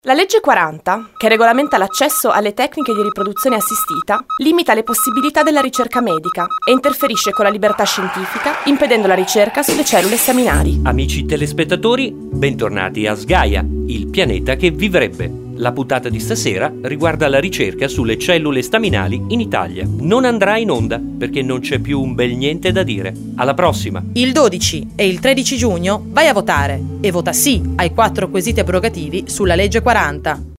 SPOT 1 | sgaia